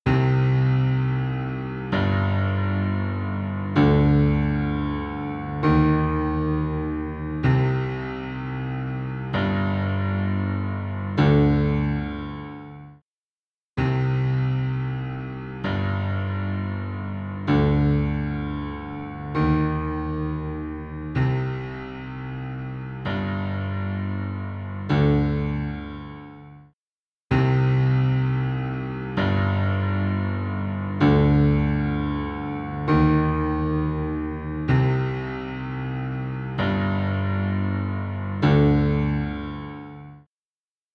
EChannel | Piano | Preset: LA-5A Compressor
EChannel-LA-5A-Compressor.mp3